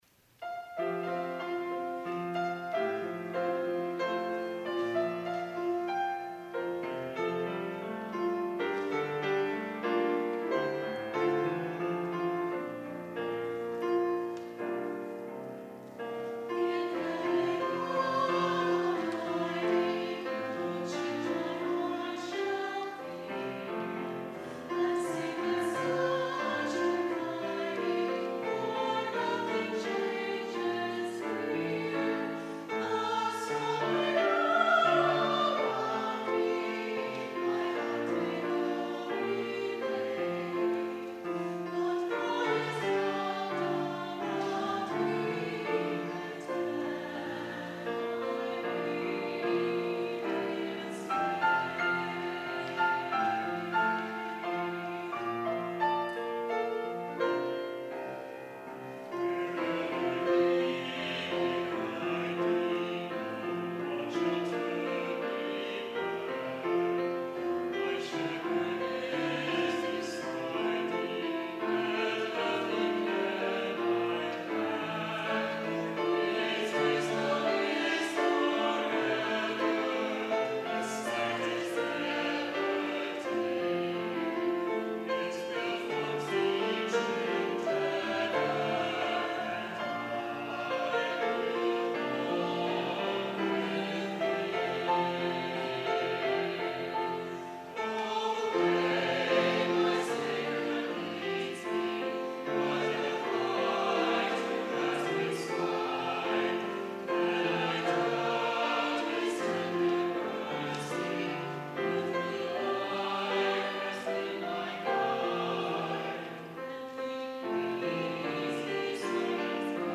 Chancel Choir
piano